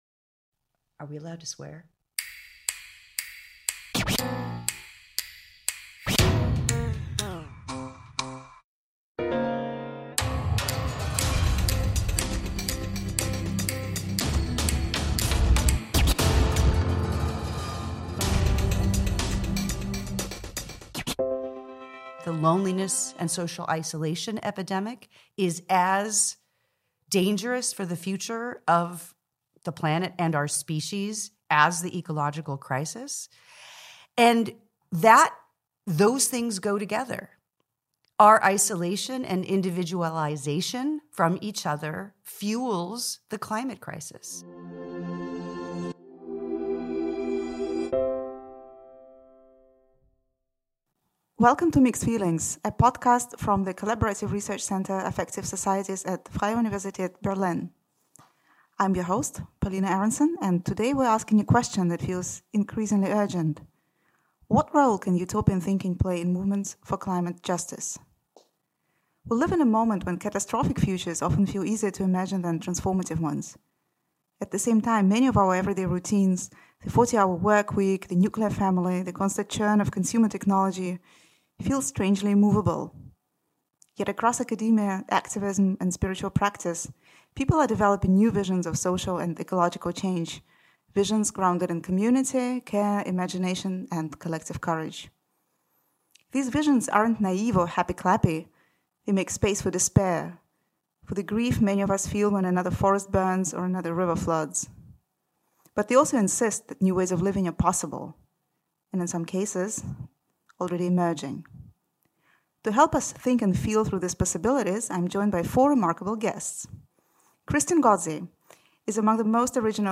In the second episode of our Mixed Feelings podcast, we explore ecological despair and grief—and the role that utopian thinking, emotion, and faith can play in movements for climate justice. At a moment when catastrophic futures often seem easier to imagine than transformative ones, the episode asks what it takes to envision change without denying loss, exhaustion, or fear. In this conversation